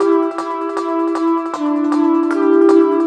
Track 11 - Tone Bells 03.wav